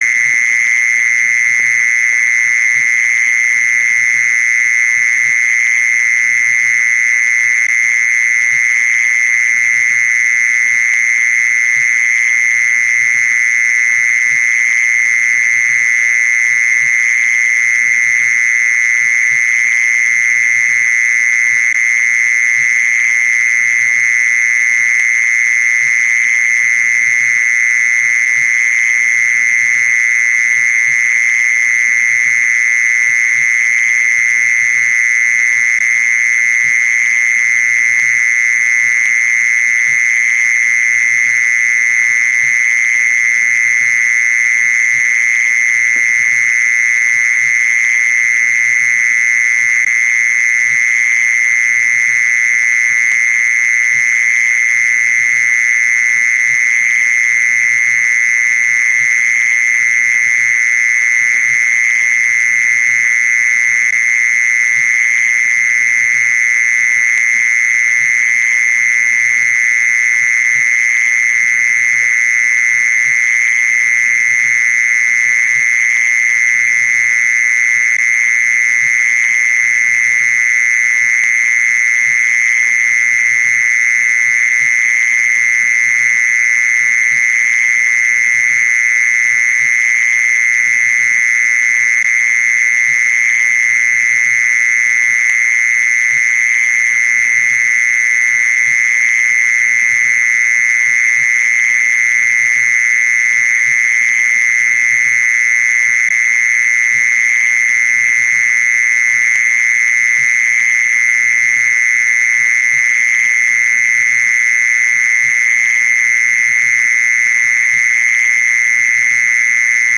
โหลดเสียงแมงจีซอน MP3
เสียงแมงจีซอน
แท็ก: เสียงจิ้งหรีดร้อง เสียงแมลง